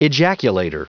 Prononciation du mot ejaculator en anglais (fichier audio)